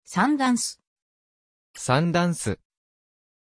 Aussprache von Sundance
pronunciation-sundance-ja.mp3